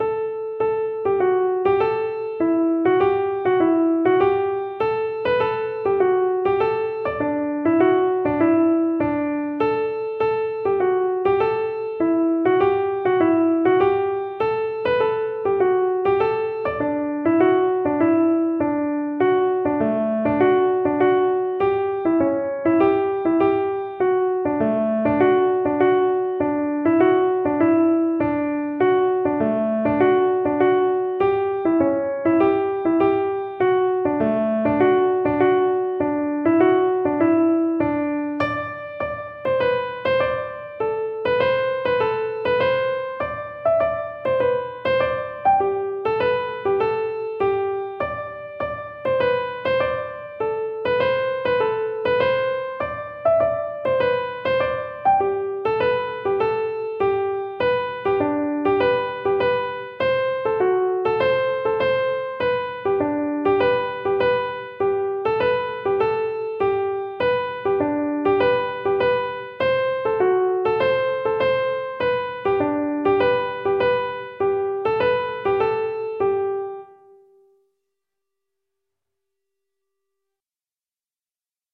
Each tune is available as a PDF (sheet music) and MP3 (audio recording played slowly for learning).